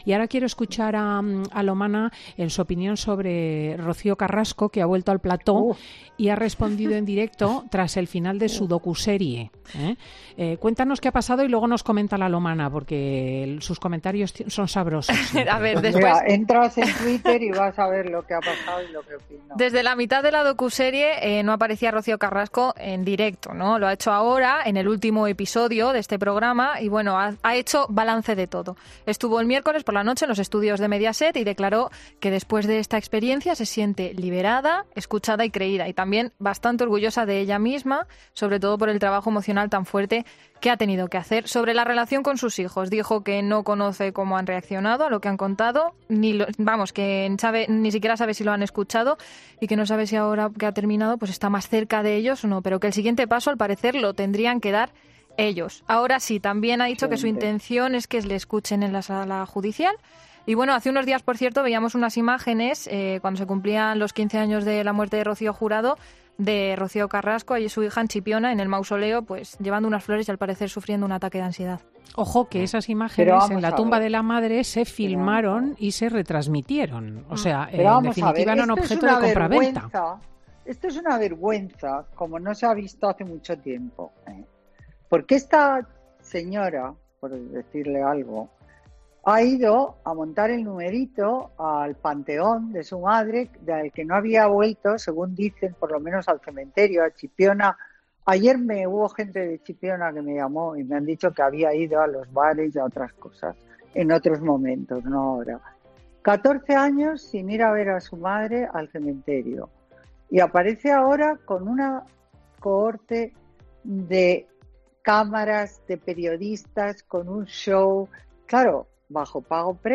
La socialité analiza en Fin de Semana el anuncio de una segunda parte del documental de Telecinco sobre la hija de Rocío Jurado